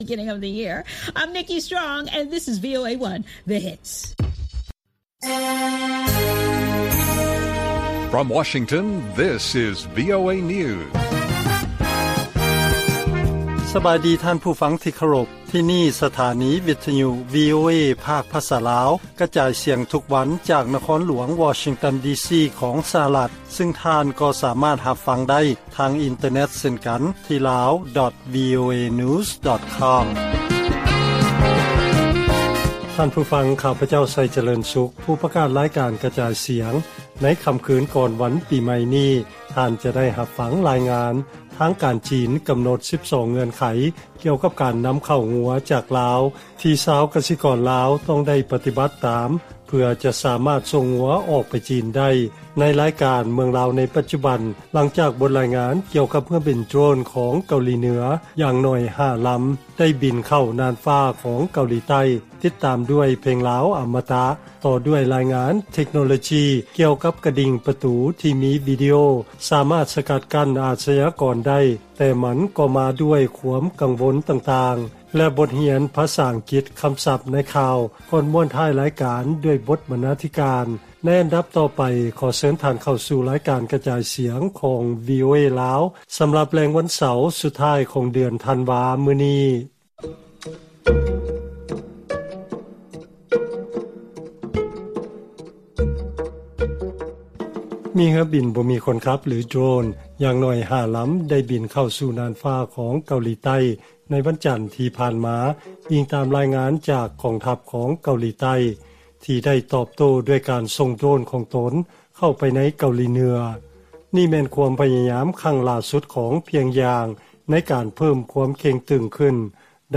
ລາຍການກະຈາຍສຽງຂອງວີໂອເອ ລາວ: ເກົາຫຼີເໜືອ ສົ່ງໂດຣນເຂົ້າໄປໃນ ເກົາຫຼີໃຕ້ ແບບບໍ່ຮູ້ສຶກອາຍເລີຍ